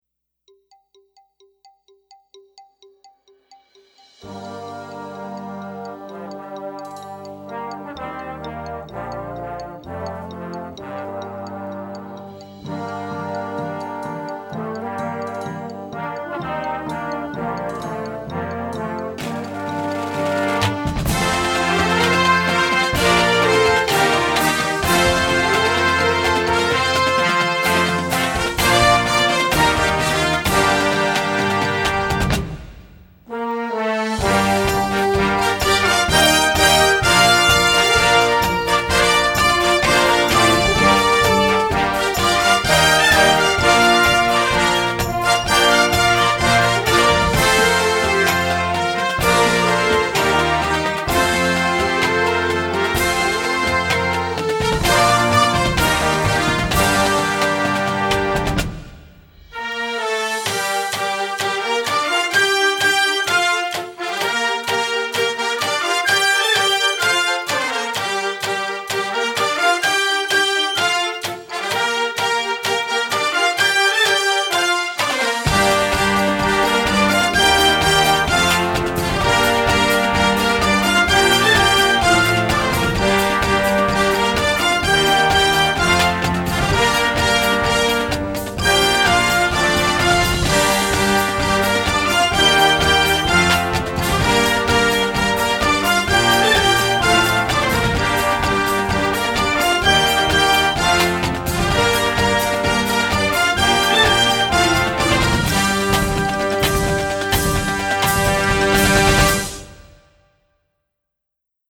Gattung: Marching Band Series
Besetzung: Blasorchester
With an anthem-like rock feel and distinctive brass riff
Upbeat and powerful!